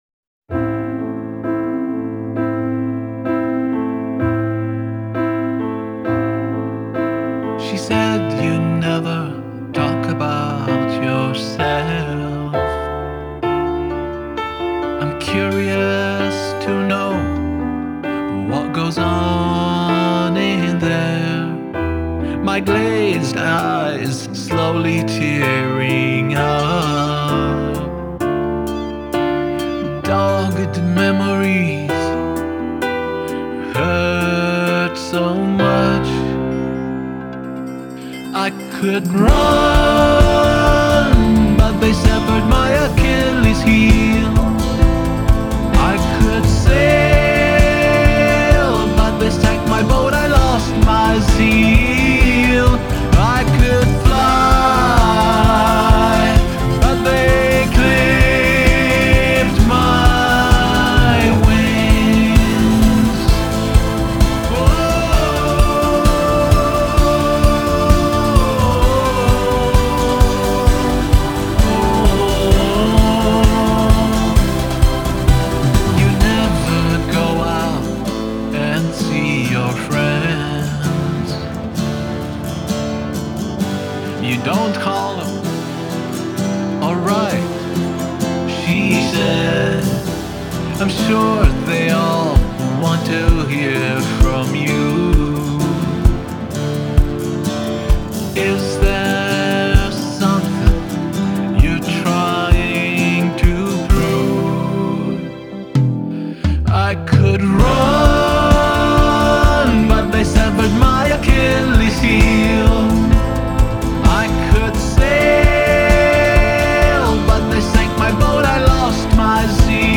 Genre: Pop Rock, Adult Alternative Pop/Rock